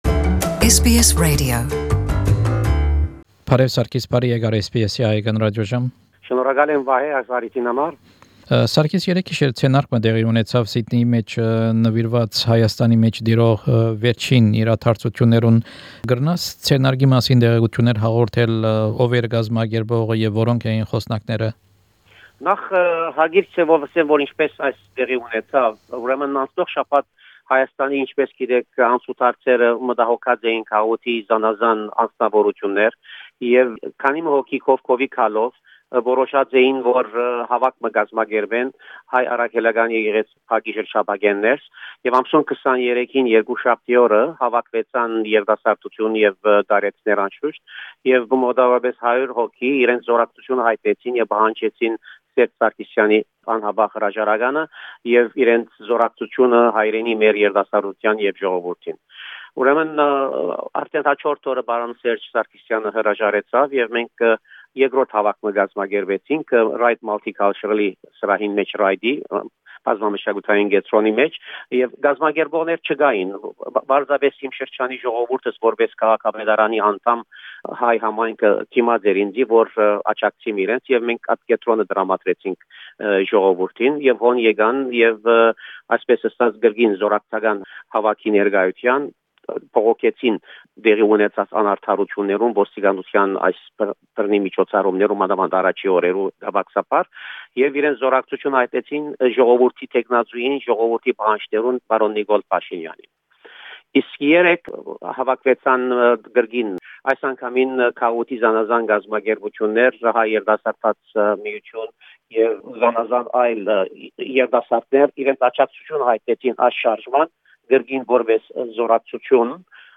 Հարցազրոյց Ռայտ քաղաքապետարանի խորհուրդի անդամ Պրն Սարգիս Ետելեանի հետ: